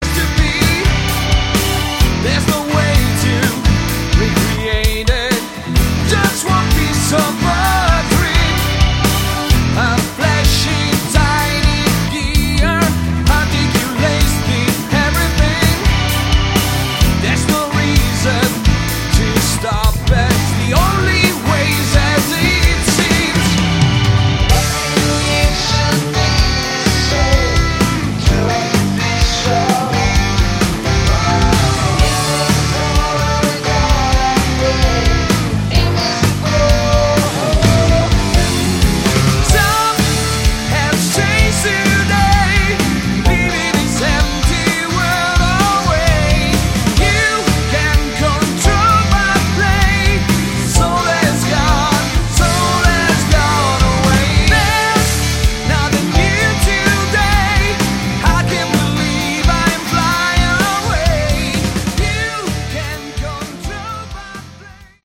Category: Melodic Rock
lead and backing vocals
guitars, keyboards, violin